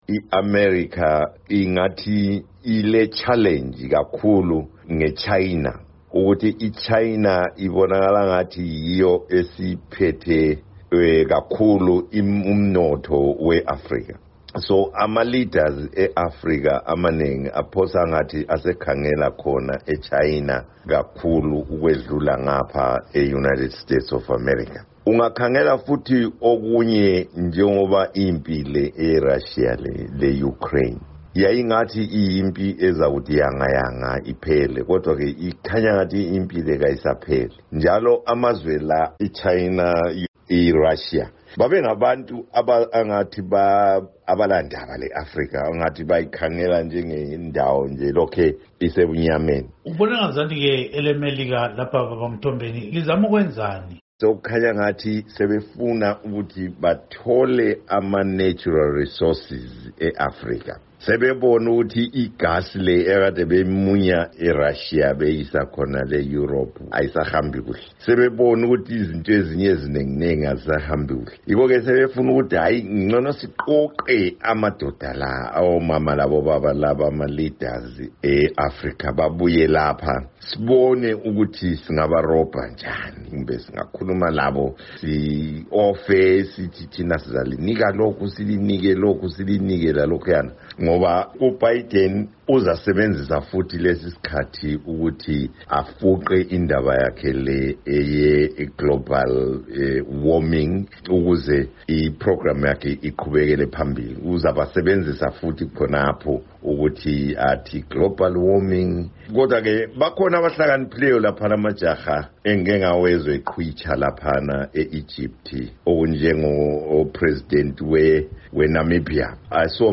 Amazwi kaMongameli Joe Biden